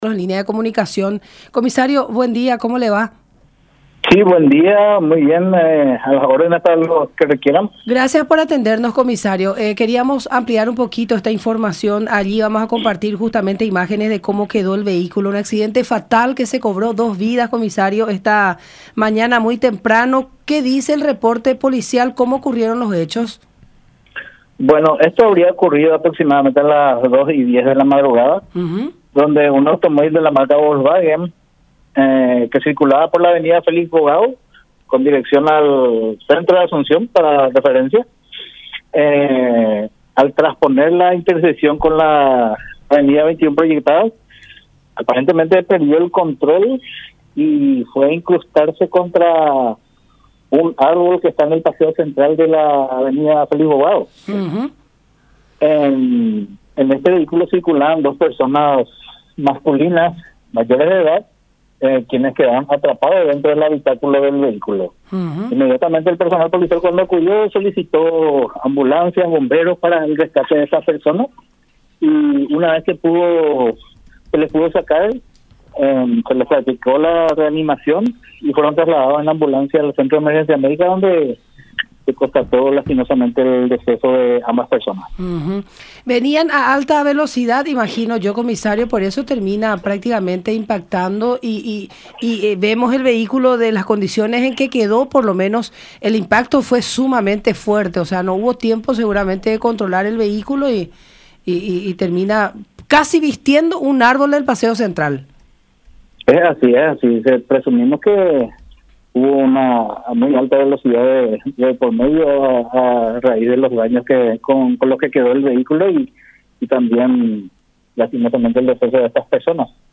“Presumimos que hubo una alta velocidad de por medio, en ese horario caía una pertinaz llovizna y la pista mojada podría haber sido un facto que ocasiono la perdida del control del vehículo” , relató en el programa “La Mañana De Unión” por radio La Unión y Unión Tv.